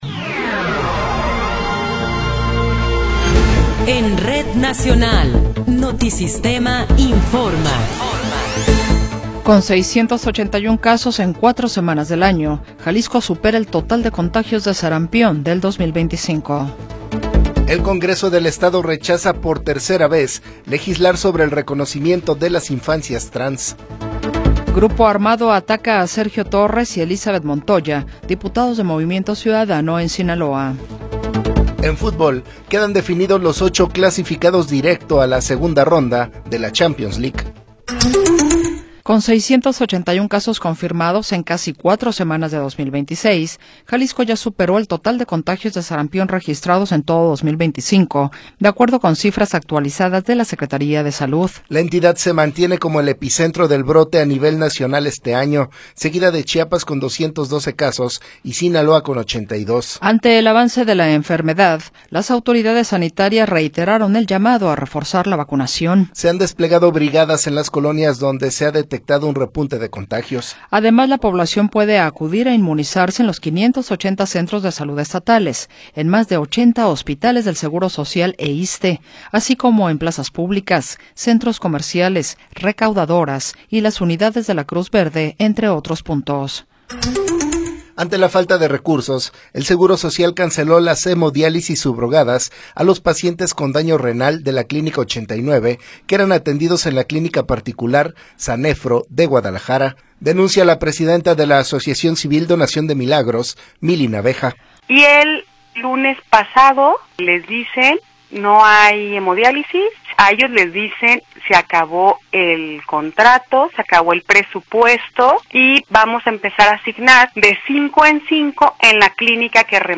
Noticiero 20 hrs. – 28 de Enero de 2026